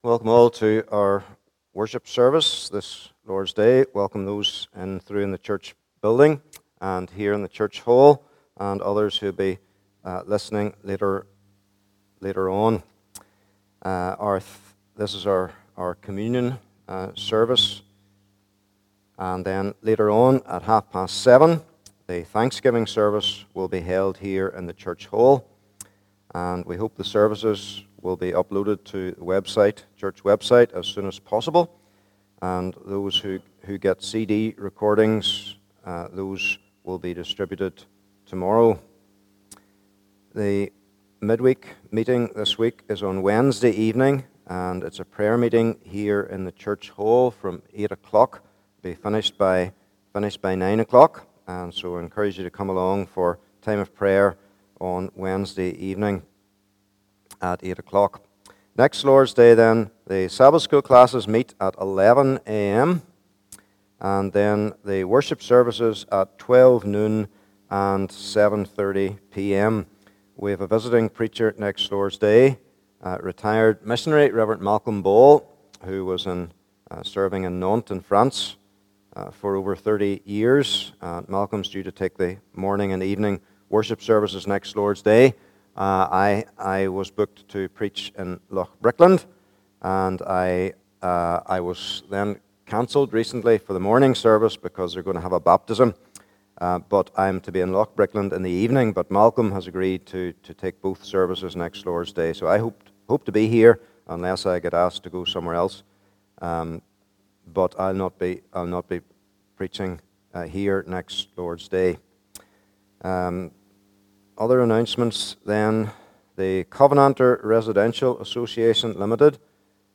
Communion May 2021 Passage: Matthew 27 : 32 - 40 Service Type: Morning Service « Jesus the True Bread of God Jesus Our Substitute